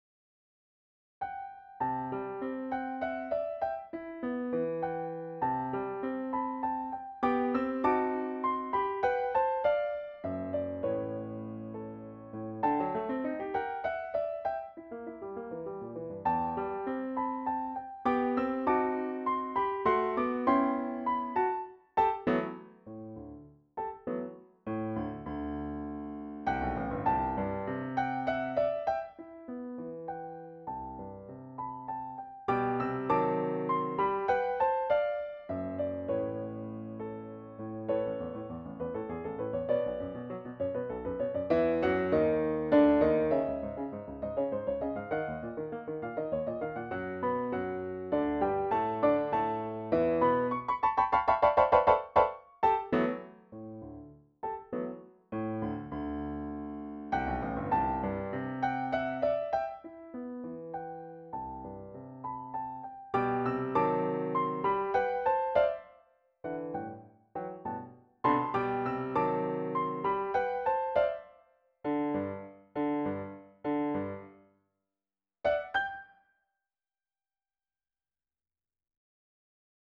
Shitpost l - Piano Music, Solo Keyboard - Young Composers Music Forum